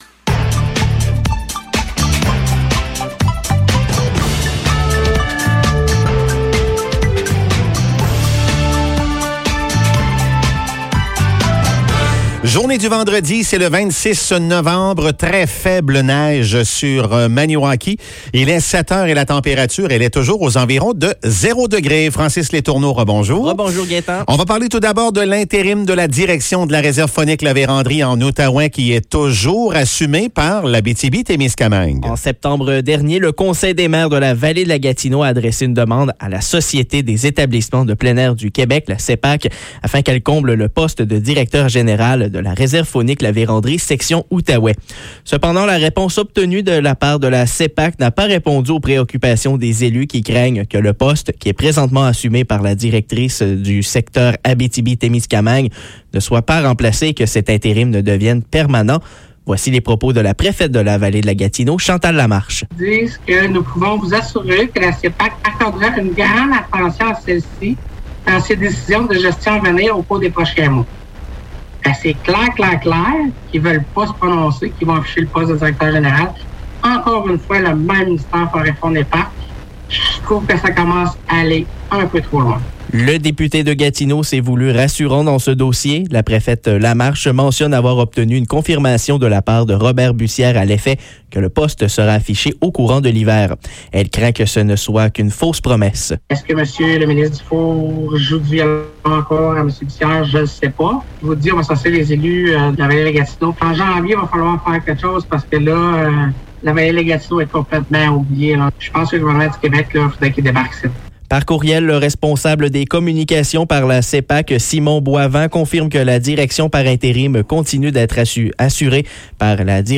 Nouvelles locales - 26 novembre 2021 - 7 h